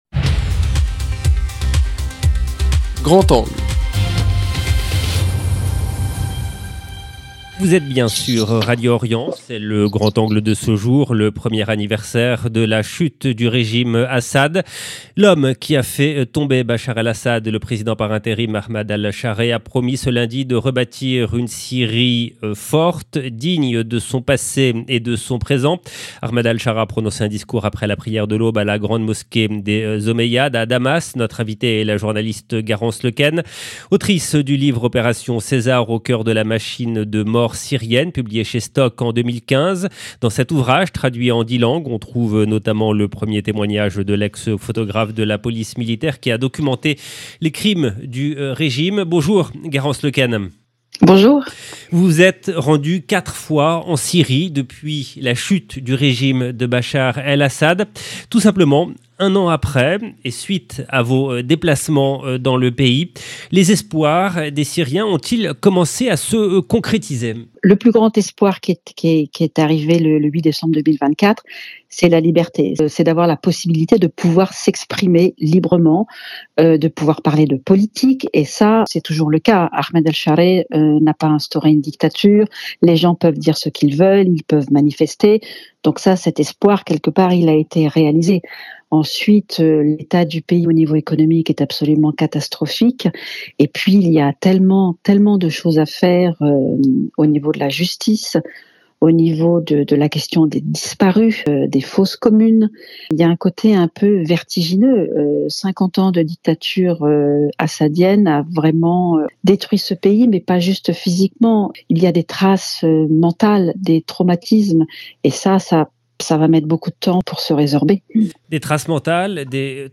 L’entretien explore une question centrale : un an après la chute du régime, les espoirs des Syriens commencent-ils réellement à se concrétiser ? 0:00 11 min 42 sec